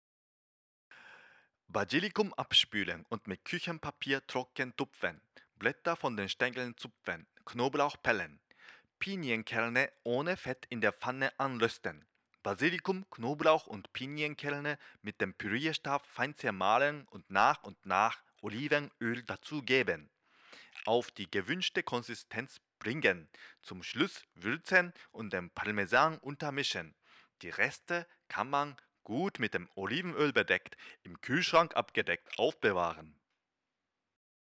Sprecher japanisch, Profisprecher, für Werbung und Industrie
Kein Dialekt
Sprechprobe: Industrie (Muttersprache):